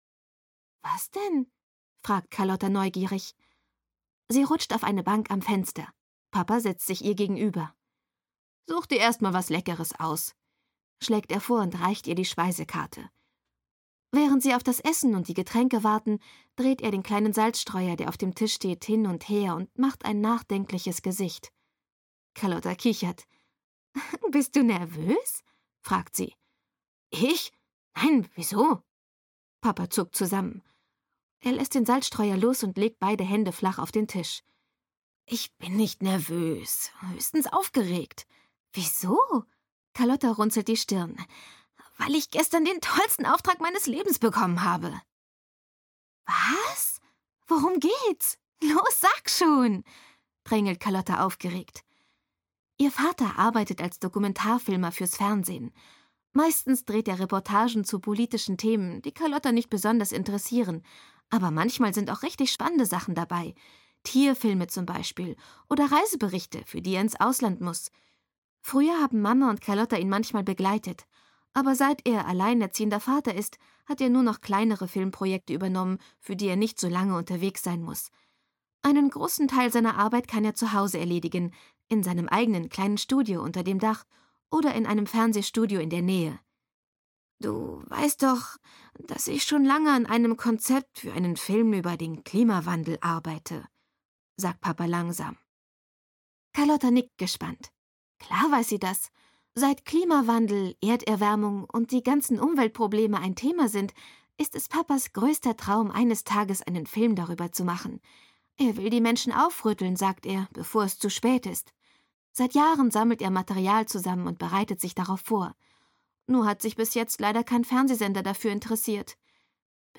Carlotta 1: Carlotta - Internat auf Probe - Dagmar Hoßfeld - Hörbuch